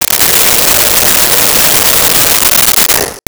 Blow Dryer 02
Blow Dryer 02.wav